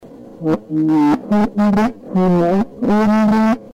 In questa brevissima registrazione ricevuta il 24 novembre 2008, si presenta un'entità sconosciuta che, però, dal tono che usa, sembra avere una certa autorità.